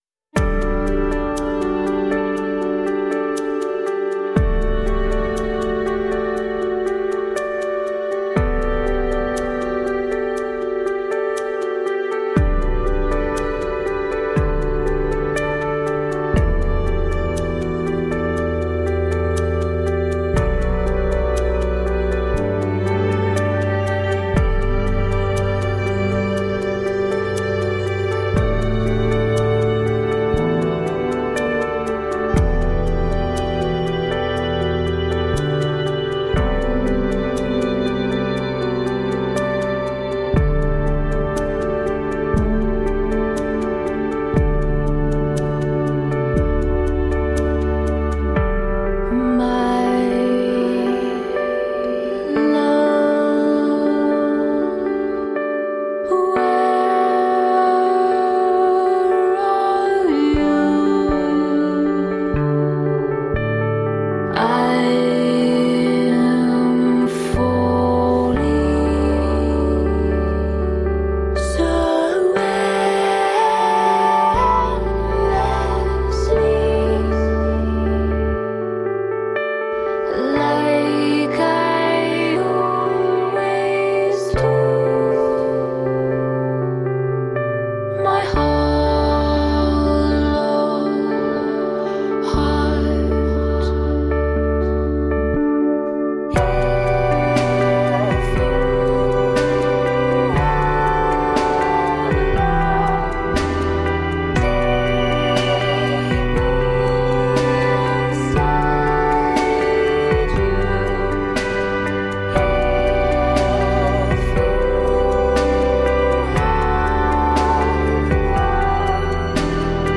Pretty pop that sounds like jewel-tone colors.